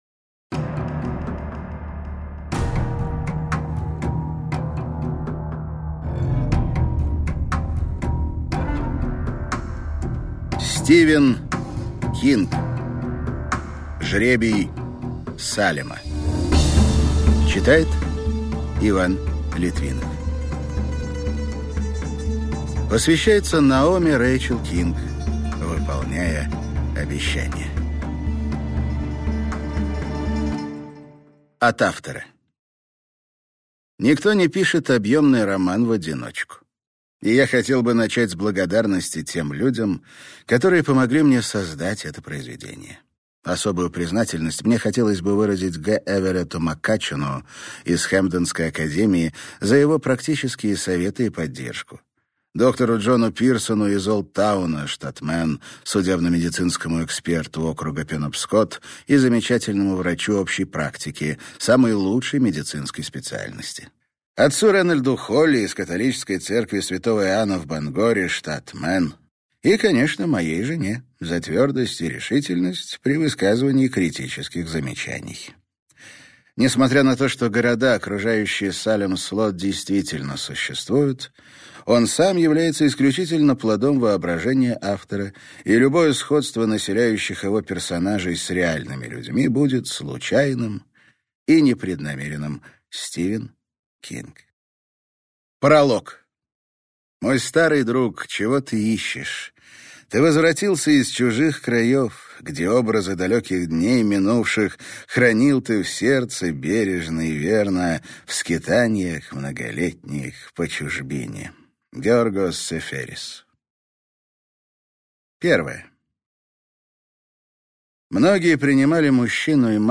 Аудиокнига Жребий Салема - купить, скачать и слушать онлайн | КнигоПоиск